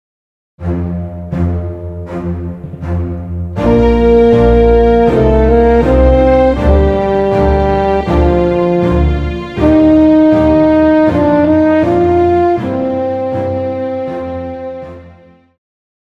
Classical
French Horn
Band
Instrumental
Only backing